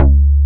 BAS_Jupiter 8 17.wav